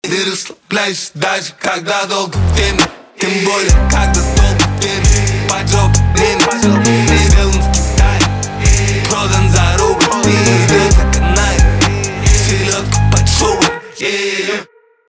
русский рэп
битовые , басы